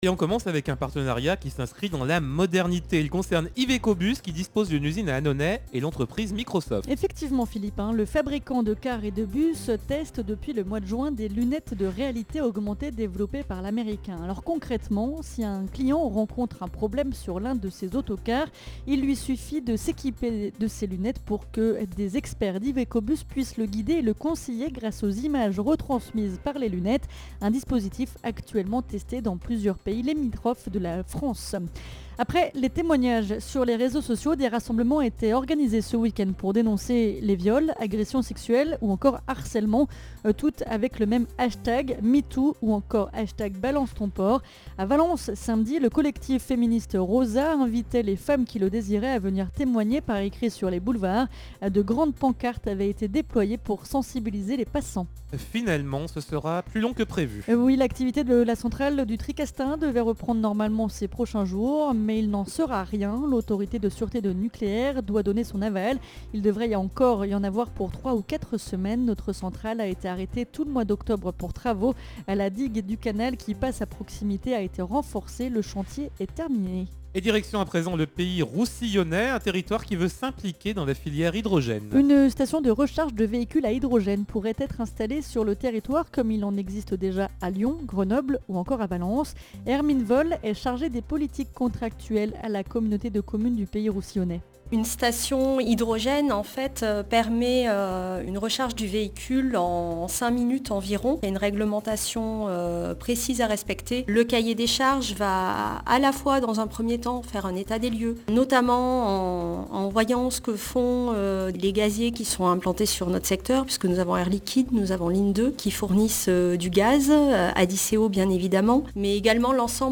in Journal du Jour - Flash